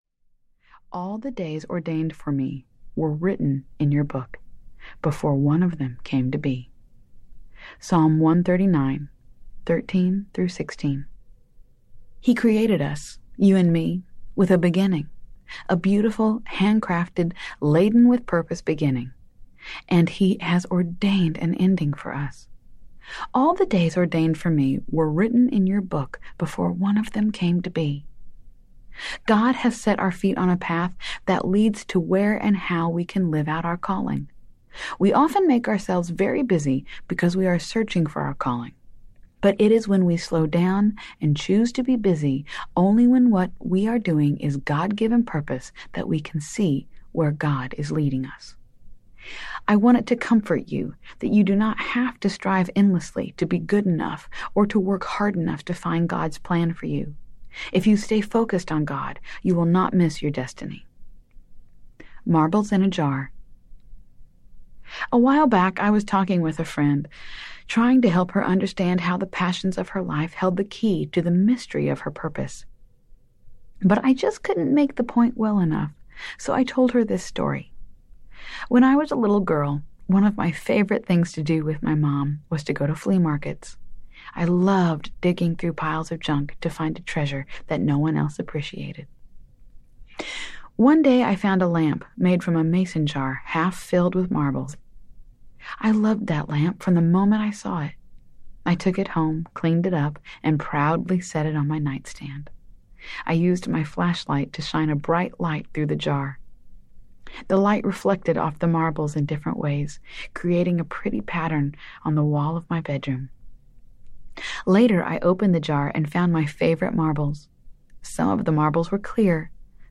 Breaking Busy Audiobook
Narrator
5.82 Hrs. – Unabridged